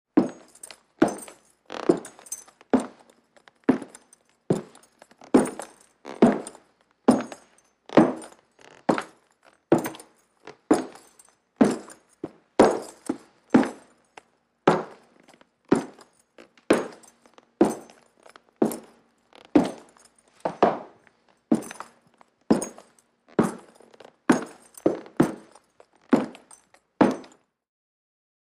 FootstepsBoots PE770101
FOLEY FOOTSTEPS BOOTS: INT: Western boots & spurs on wood, slow walk, echo.